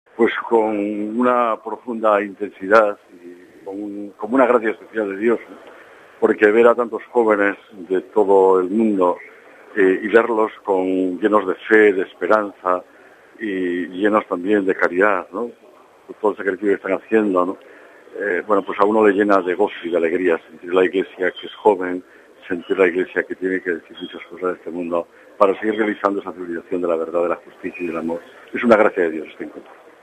Monseñor Carlos Osoro, Arzobispo de Oviedo y miembro del Comité ejecutivo de la Conferencia Episcopal Española, nos ha dicho como vive él estas jornadas: RealAudio